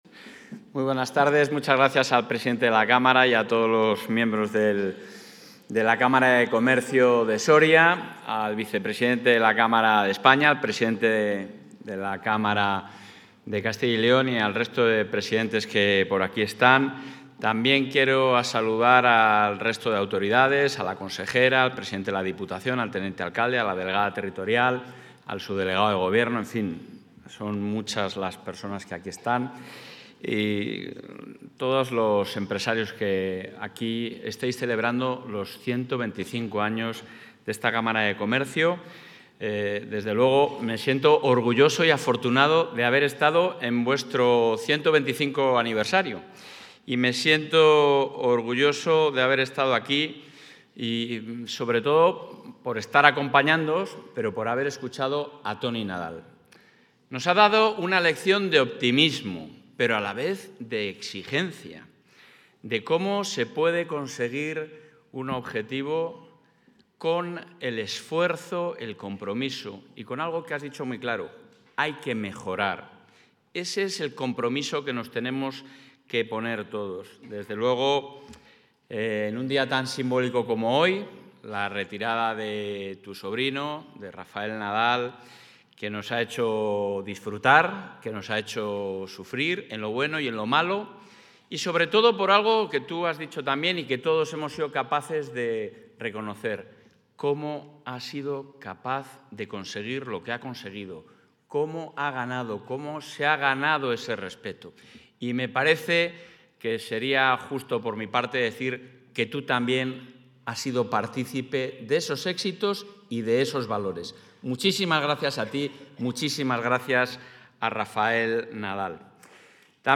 El presidente de la Junta de Castilla y León, Alfonso Fernández Mañueco, ha participado hoy en el acto conmemorativo del 125...
Intervención del presidente de la Junta.